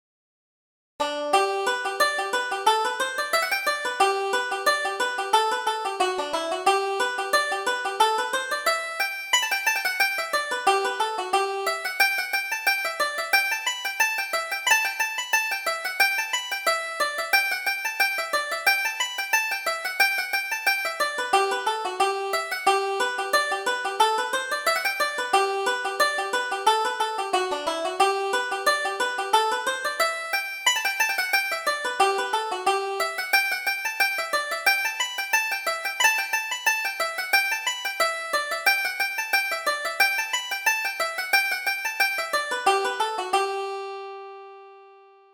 Reel: Buckley's Fancy